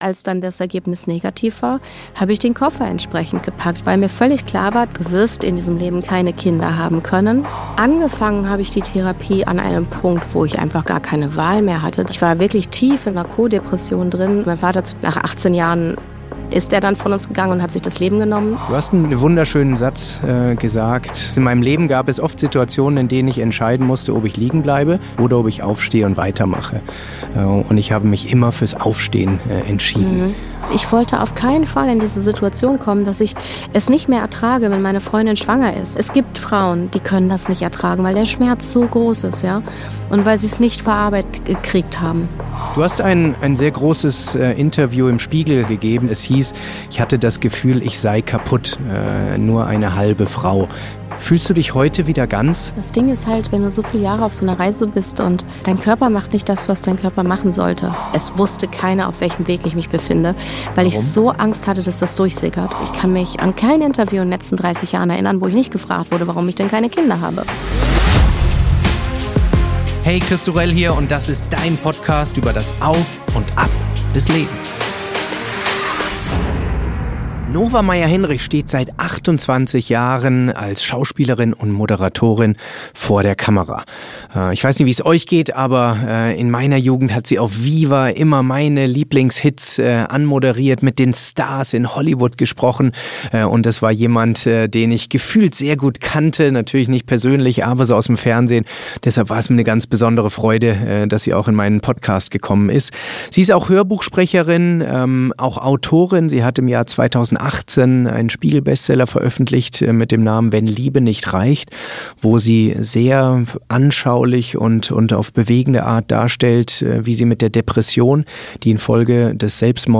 Ein SEHR persönliches Gespräch über zerplatzte Lebensträume und wie man auch in einer Sackgasse glücklich werden kann